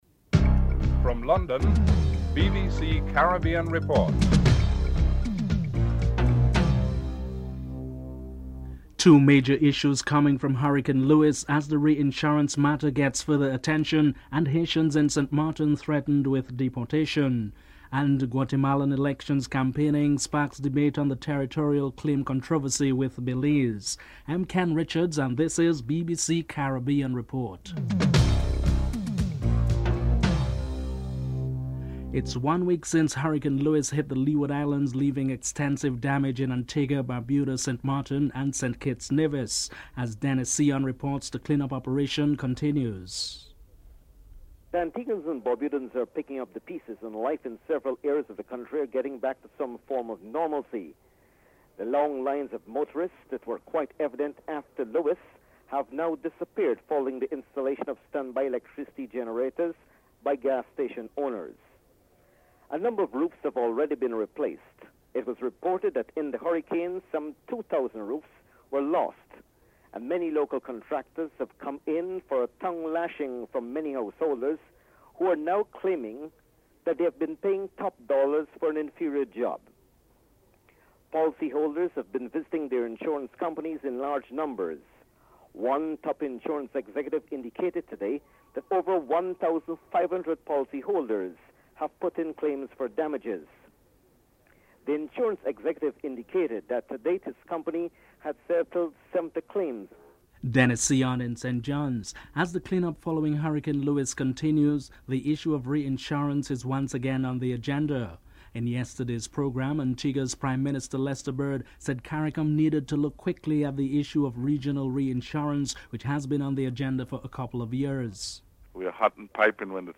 Antigua's Prime Minister Lester Bird states that CARICOM needed to look quickly at the issue of regional reinsurance.
Belize's Foreign Minister Dean Barrow comments that the situation is being closely monitored and hopes that professionalism will prevail. In Trinidad and Tobago, Prime Minister Patrick Manning denies knowledge of a death squad in the police service.